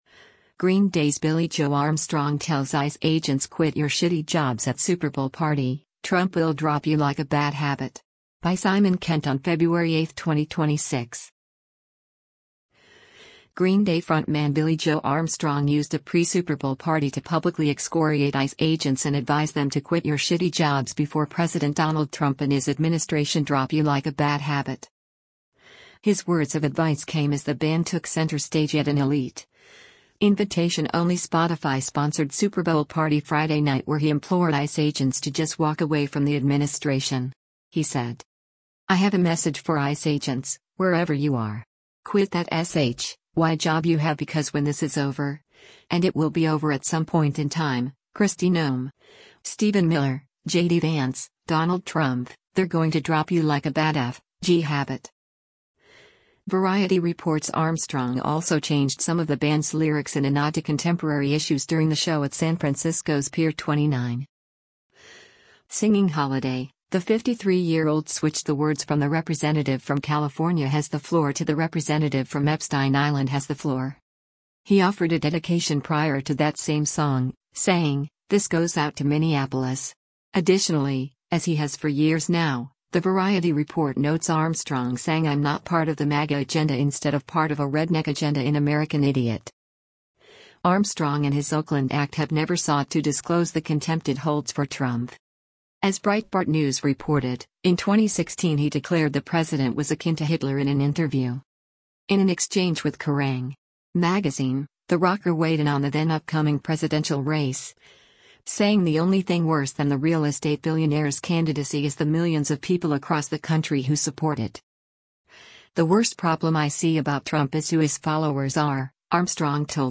during the show at San Francisco’s Pier 29